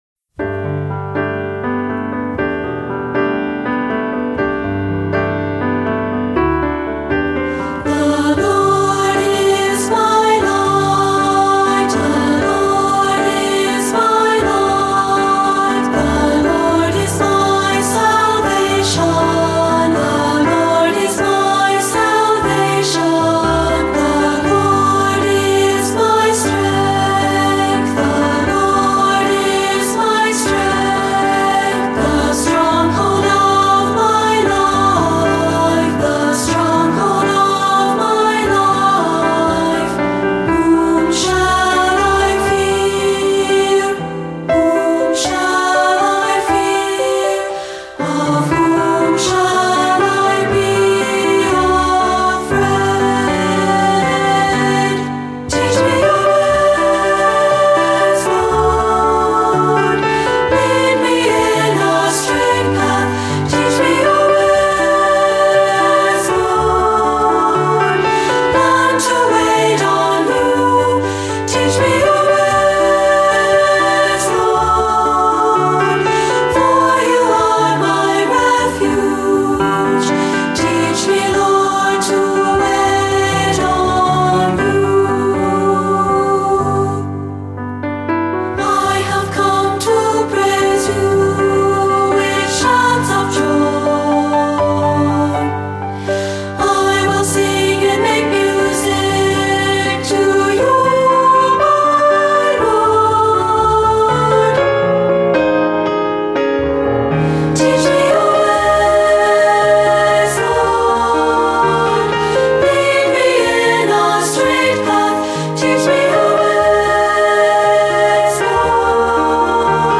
Voicing: Unison/2-Part